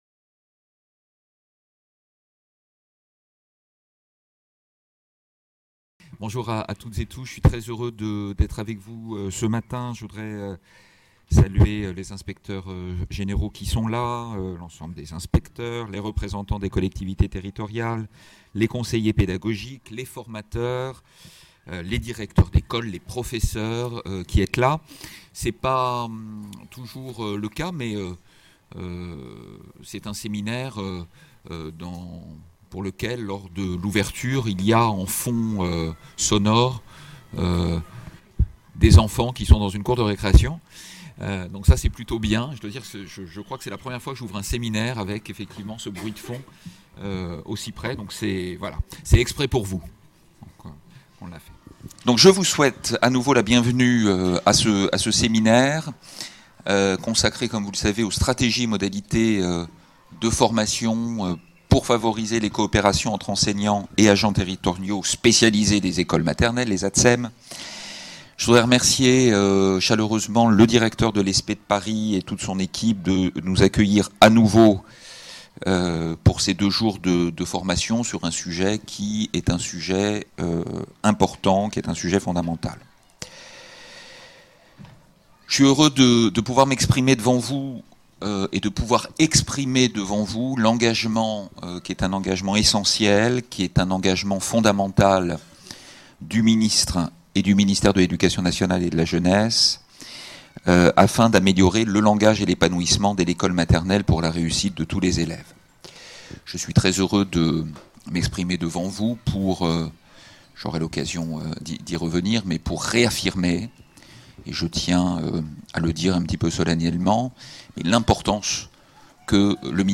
Ouverture du séminaire : Jean-Marc Huart, directeur général de l’enseignement scolaire